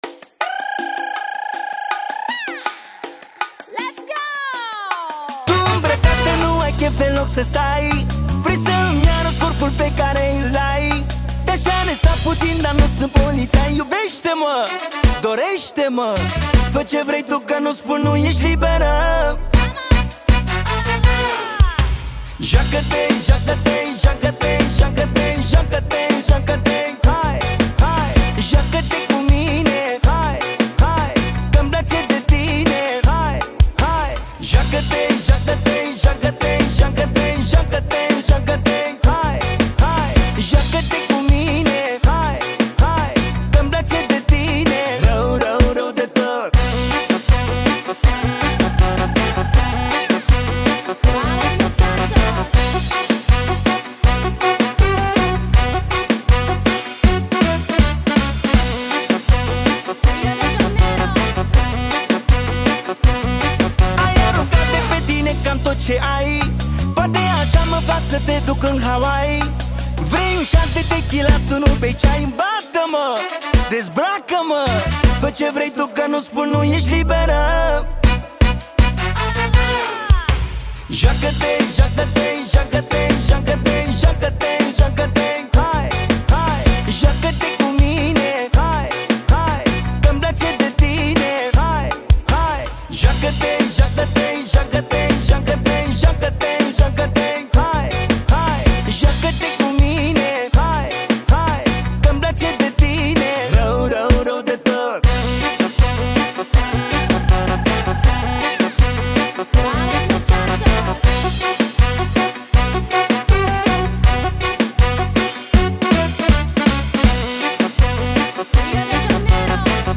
Categoria: Manele New-Live